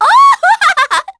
Shamilla-Vox_Happy2_kr.wav